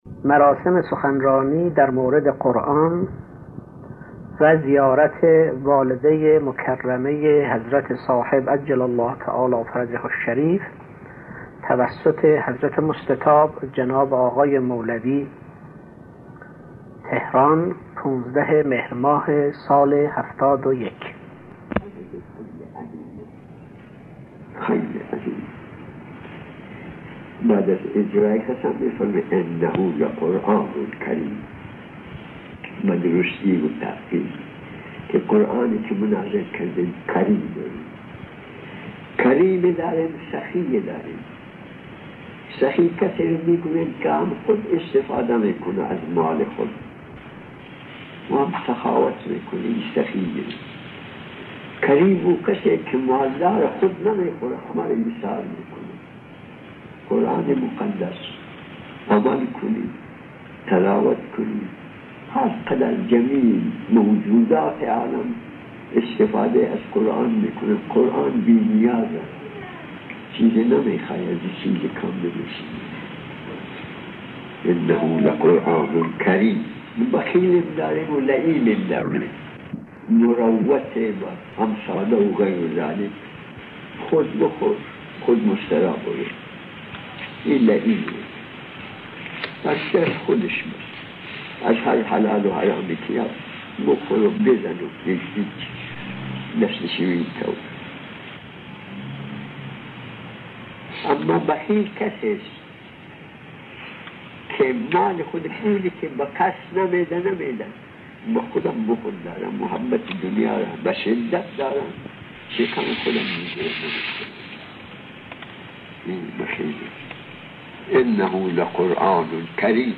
سخنرانی اخلاقی